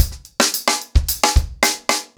TimeToRun-110BPM.15.wav